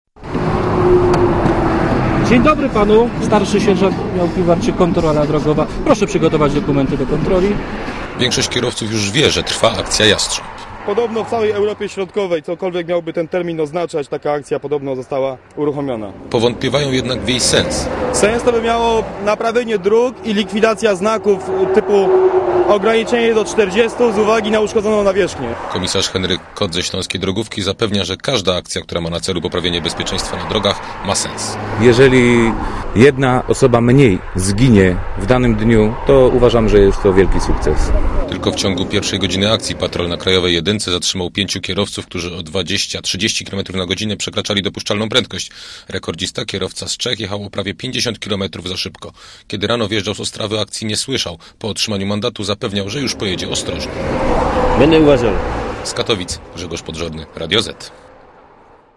kontrola_drogowa.mp3